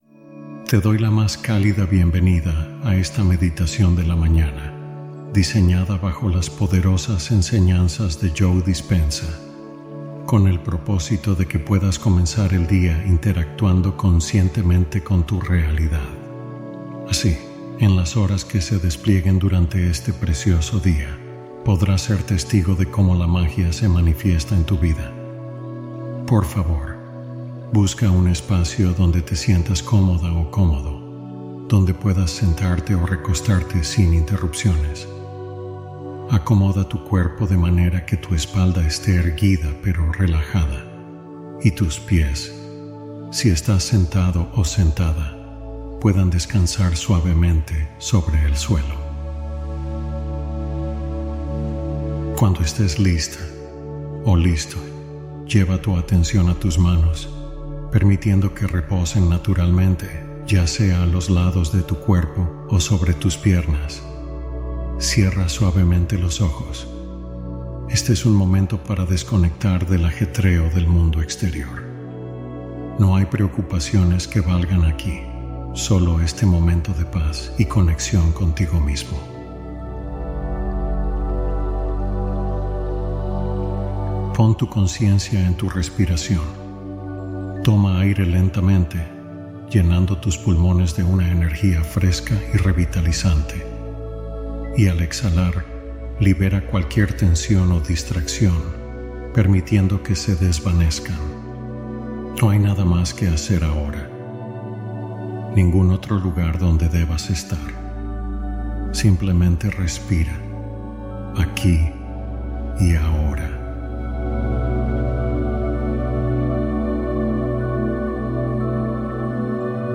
SI ESCUCHAS UNA NOCHE TODO LO BUENO LLEGARÁ A TU VIDA | Meditación y Afirmaciones